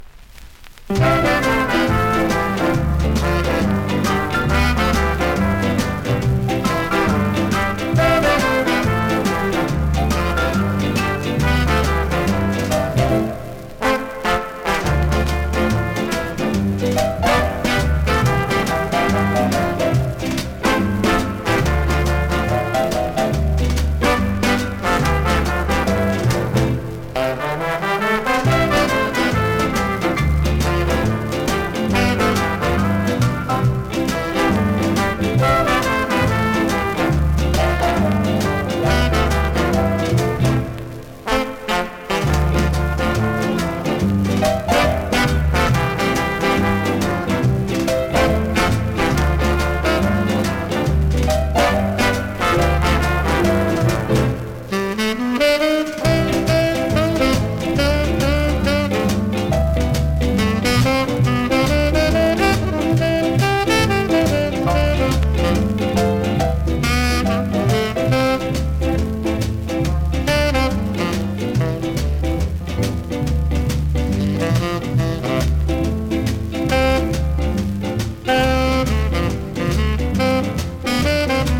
スリキズ、ノイズかなり少なめの